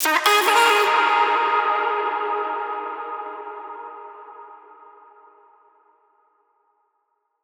VR_vox_hit_forever_D#.wav